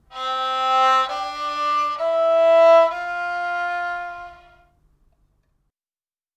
Sounds on Kamancheh strings on the assumption of basing the most prevalent tune (TUNE 1 in these instruments) are like this:
3rd string in 1st position: